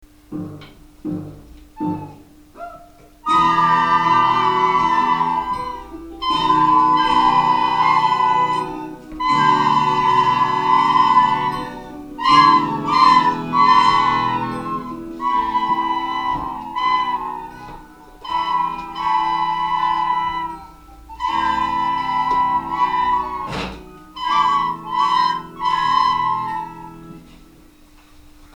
Els nens i nenes de la classe de les Fades i Follets ja han començat a tocar la flauta i sona així de bé!